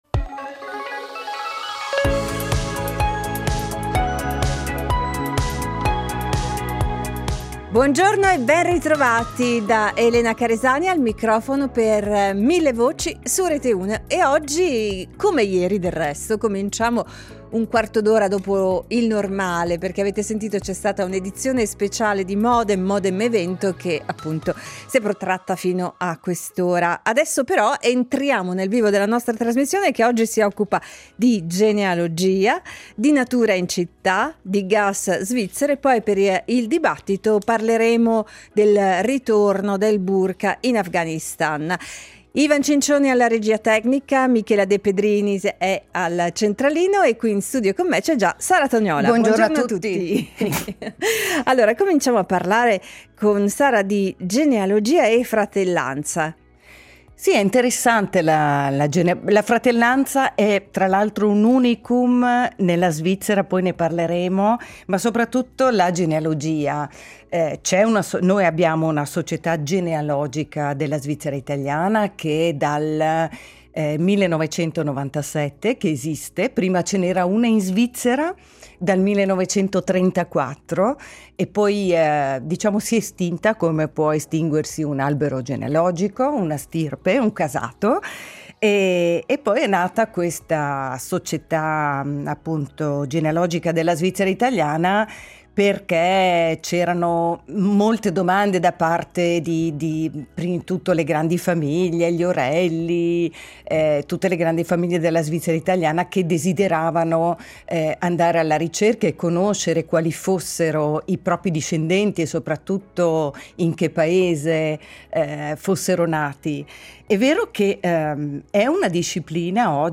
Millevoci